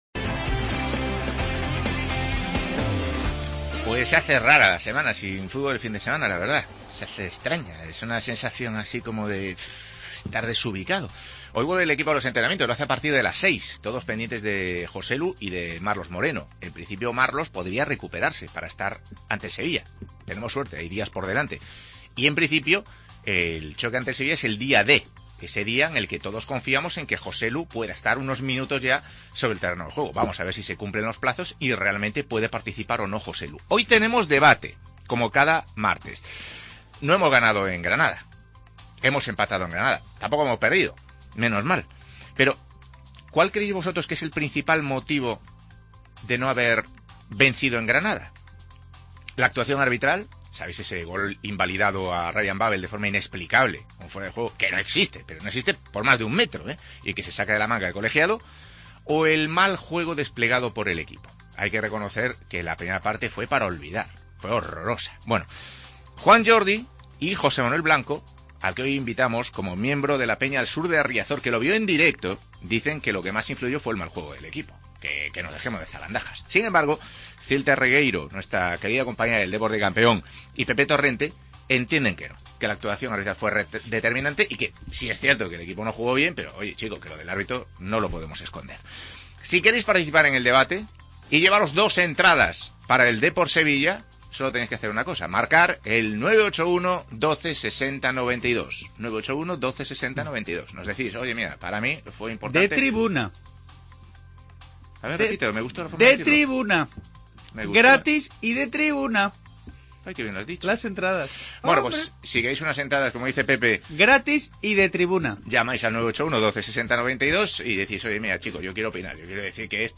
debate